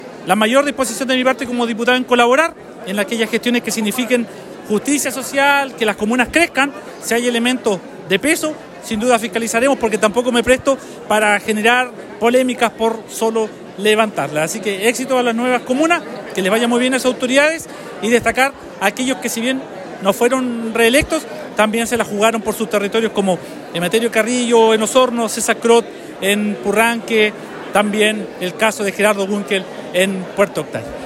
El pasado viernes, diversas autoridades nacionales y regionales participaron en la ceremonia de instalación del nuevo gobierno comunal de Osorno, encabezado por el Alcalde Jaime Bertín y su Concejo Municipal.